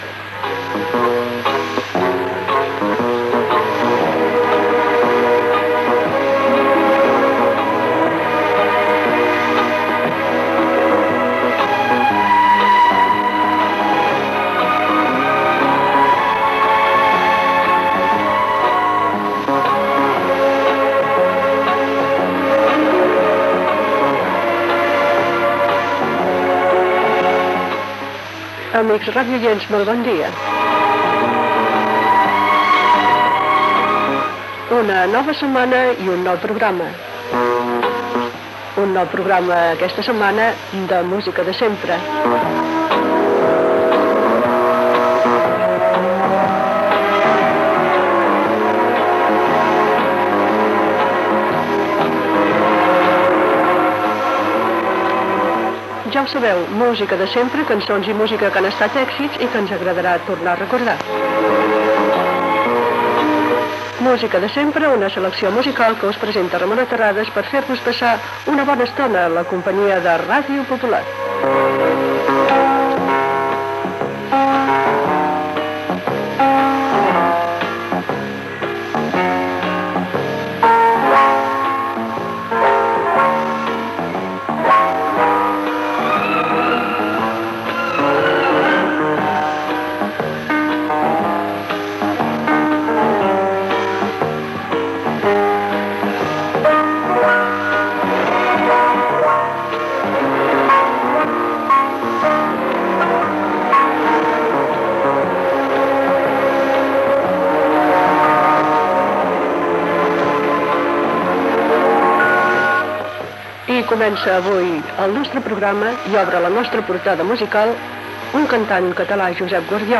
Presentació del programa i tema musical
Musical